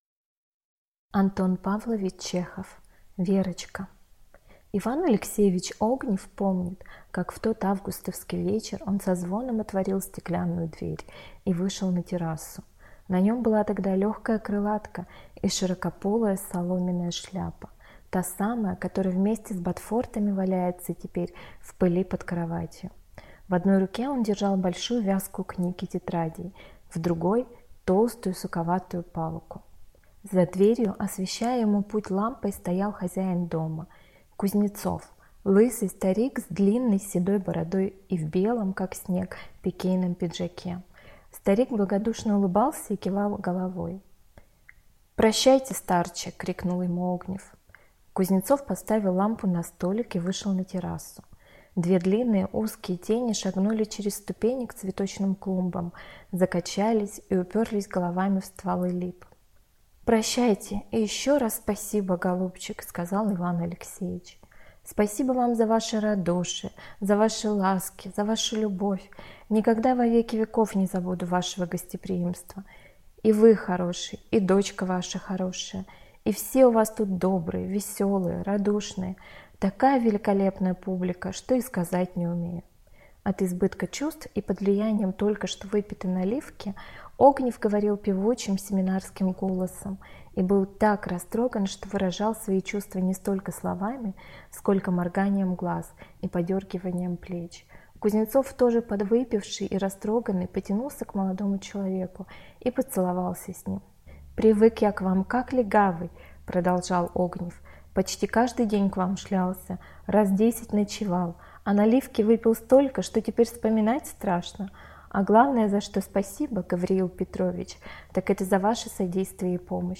Аудиокнига Верочка | Библиотека аудиокниг